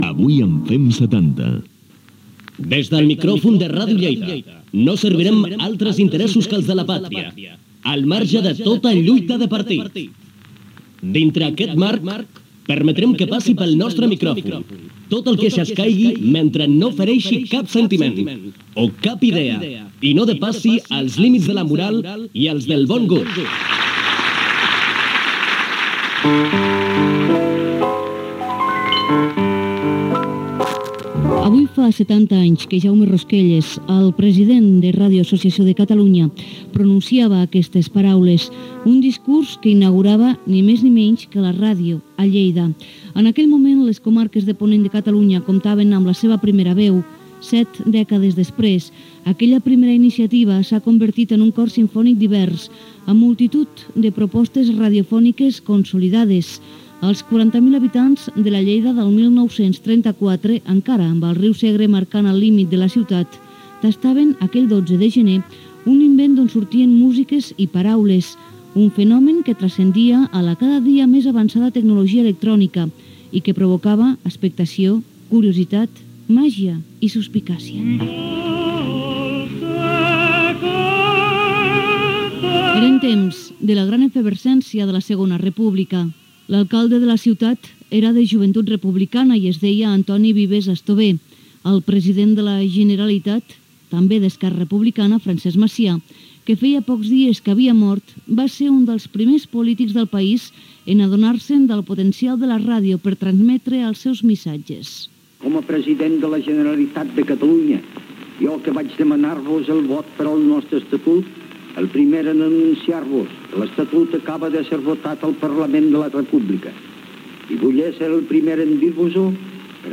Primers minuts de l'especial pel 70 aniversari de Ràdio Lleida.
Divulgació